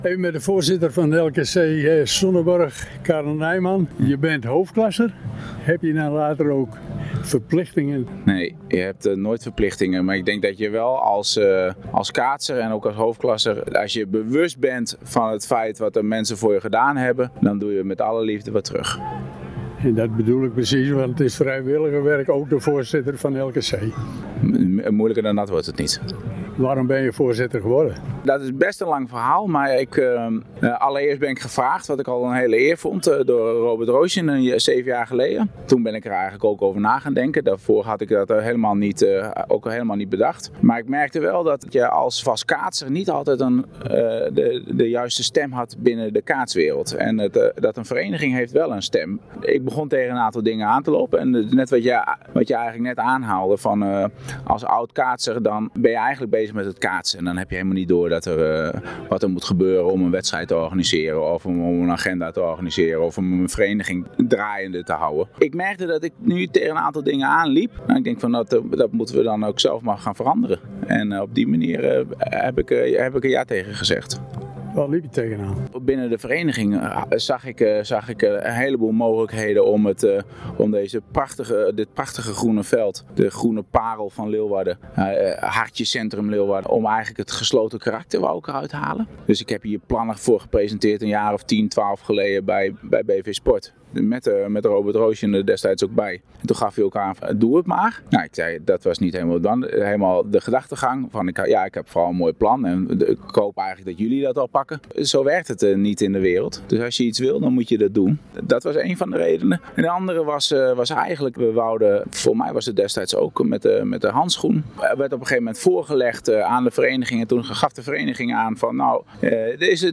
Op tien voor elf werden de ereleden, genodigden, toeschouwers en kaatsers een hartelijk welkom toegewenst